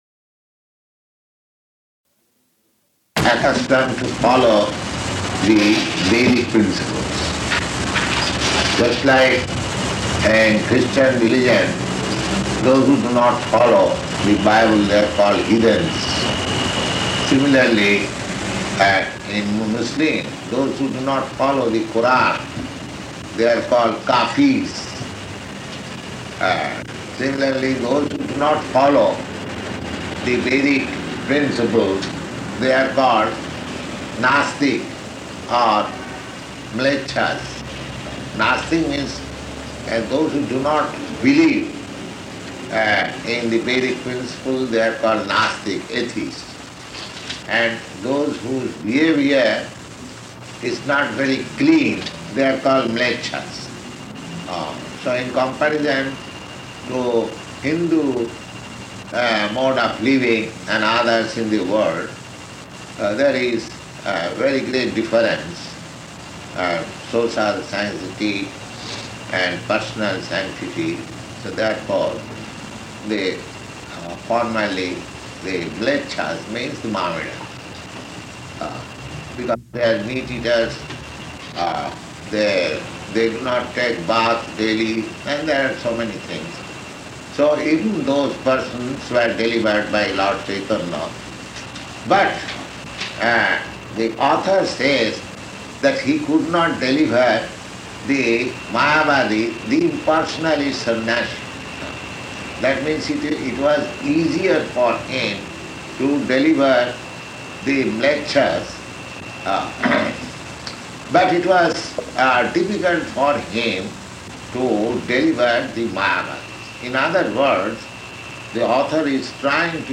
Location: San Francisco